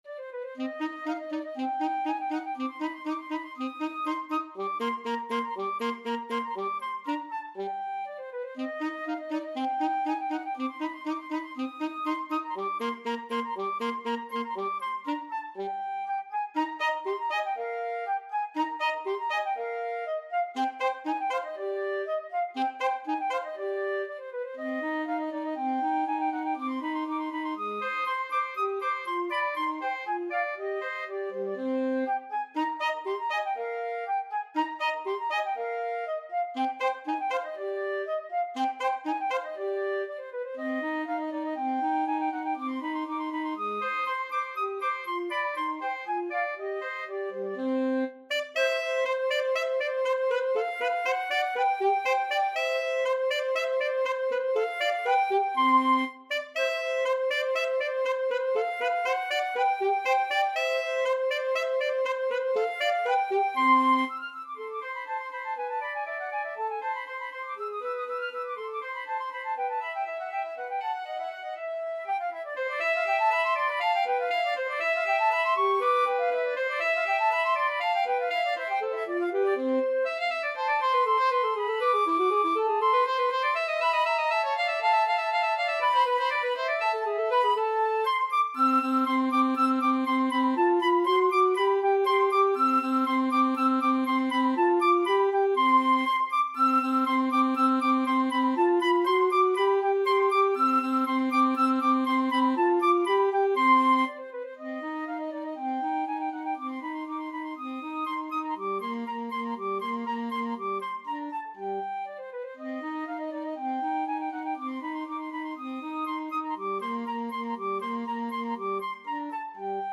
Free Sheet music for Flute-Saxophone Duet
FluteAlto Saxophone
2/4 (View more 2/4 Music)
C minor (Sounding Pitch) (View more C minor Music for Flute-Saxophone Duet )
Allegro con brio (View more music marked Allegro)
Classical (View more Classical Flute-Saxophone Duet Music)